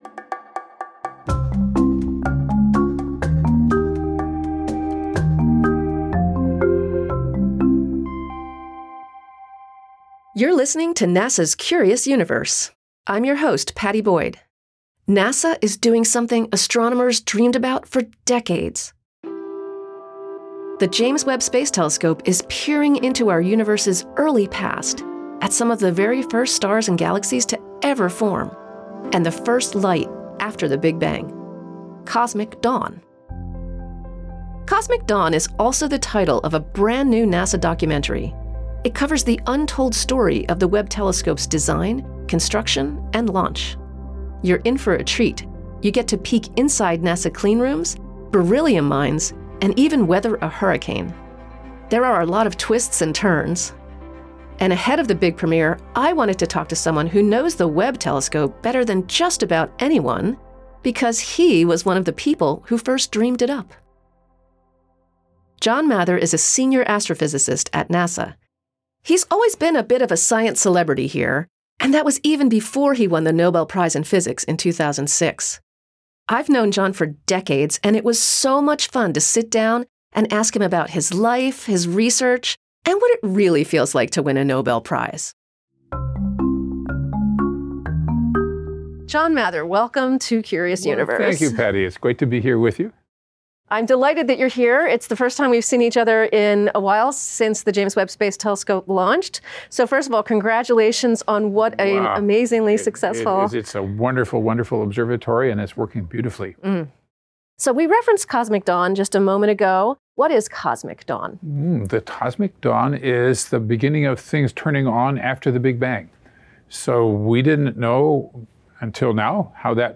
In this interview, Mather talks about his life, his research, and the pre-dawn phone call telling him he had won the Nobel Prize.